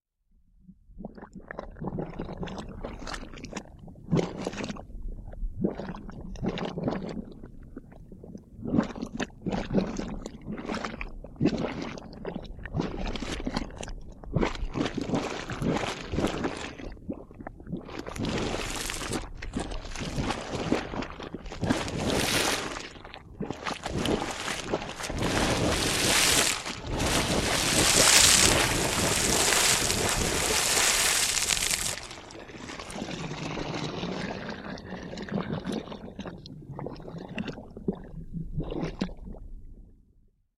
Звуки гейзеров
Гейзер медленно начинает выкипать